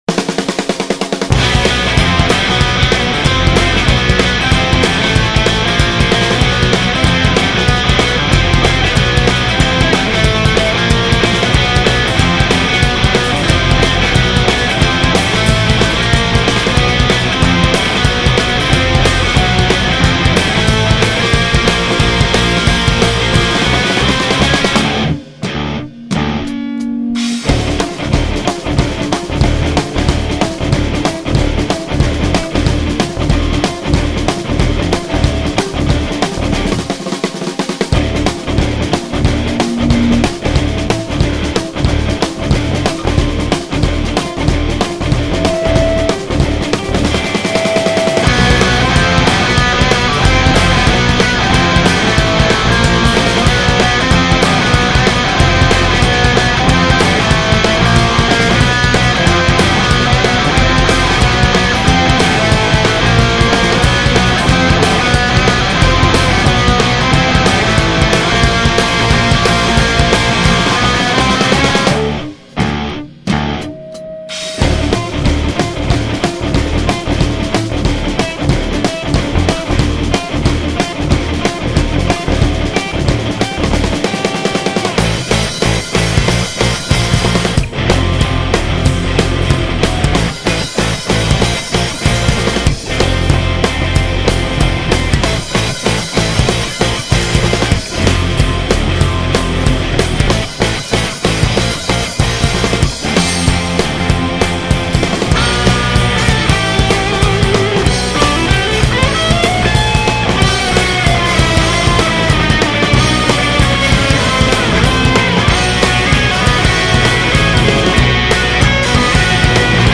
It is instrumental.